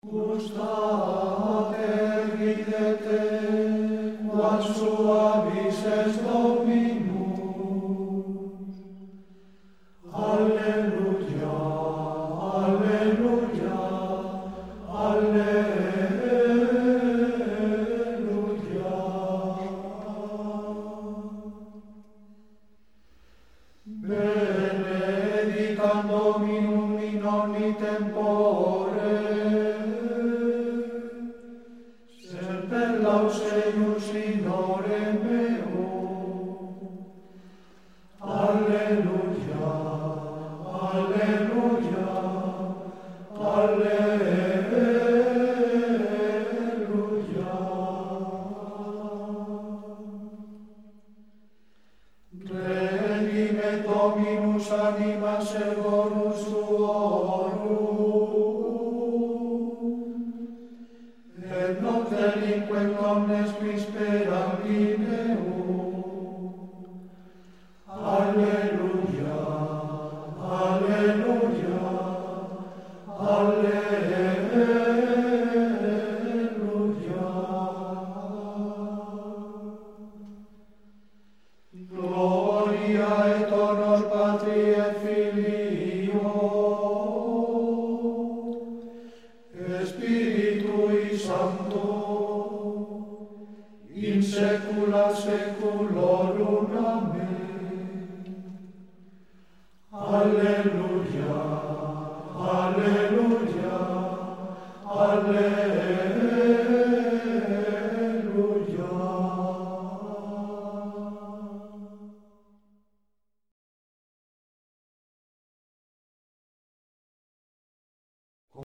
RITO HISPANO-MOZÁRABE
MÚSICA LITÚRGICA.